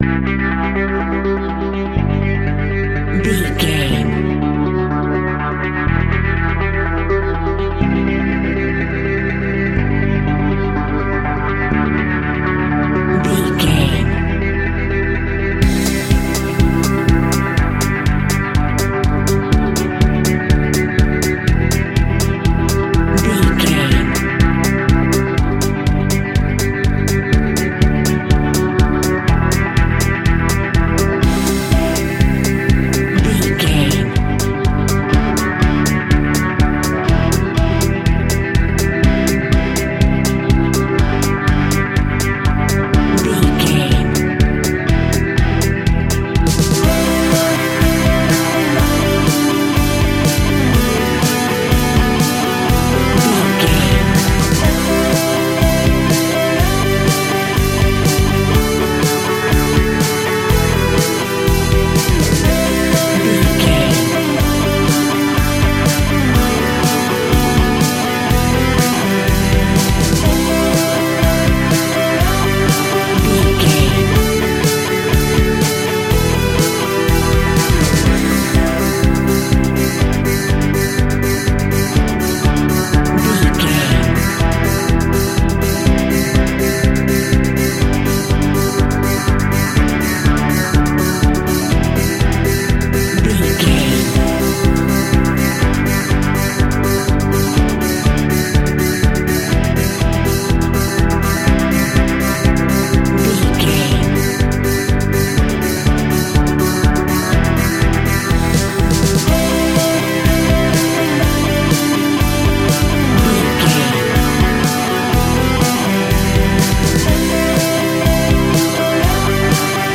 Rocking Epic Indie Pop.
Ionian/Major
energetic
uplifting
instrumentals
indie pop rock music
upbeat
groovy
guitars
bass
drums
piano
organ